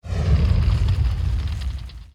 archer_skill_spyralvoltex_02_quake.ogg